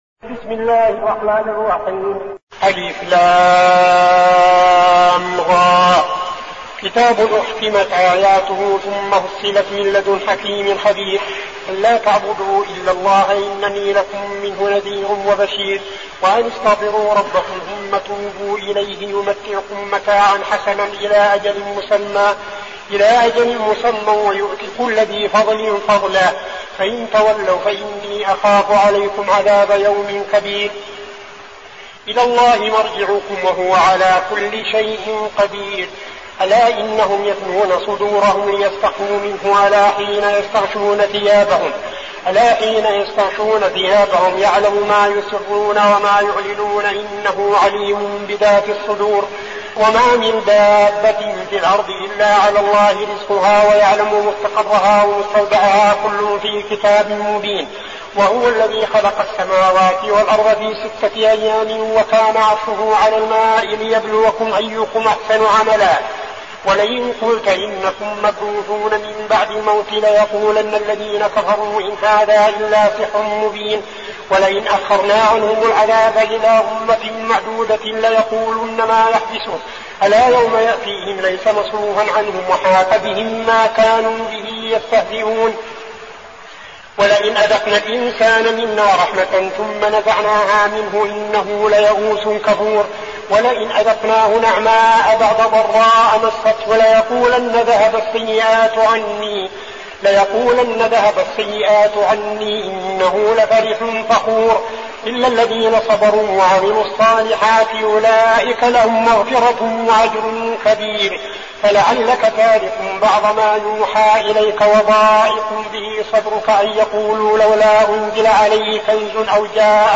المكان: المسجد النبوي الشيخ: فضيلة الشيخ عبدالعزيز بن صالح فضيلة الشيخ عبدالعزيز بن صالح هود